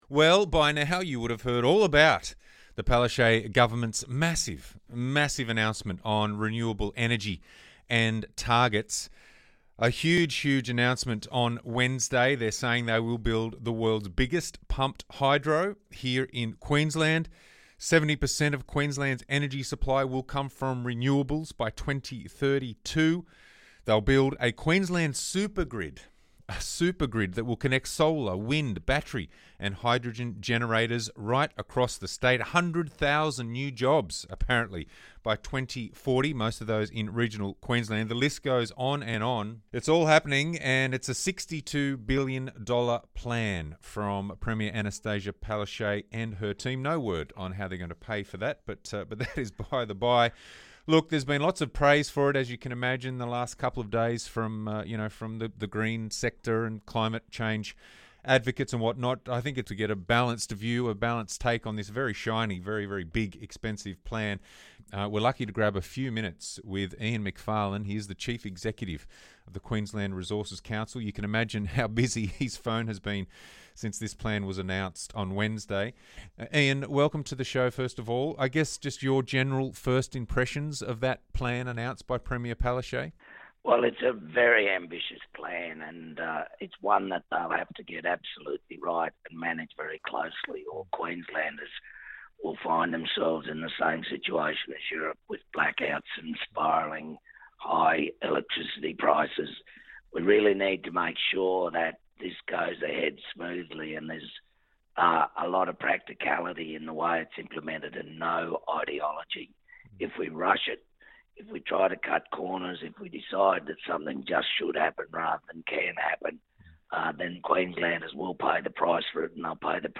The King Review - Gavin chats with Queensland Resources Council chief executive Ian Macfarlane on the QLD Government's ambitious $62 billion renewable energy plan - 30 September 2022